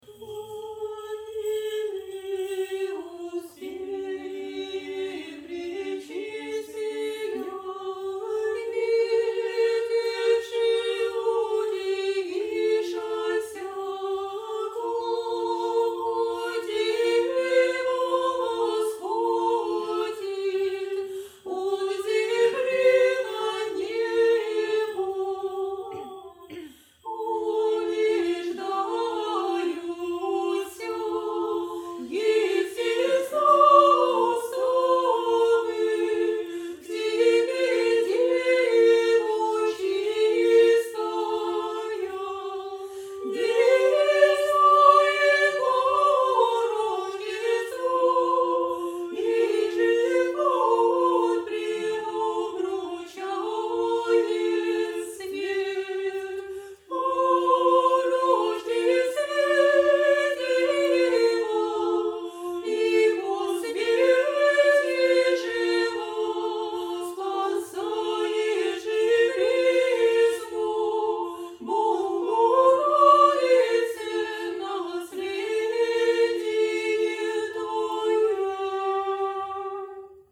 Успение-задостойник_01.mp3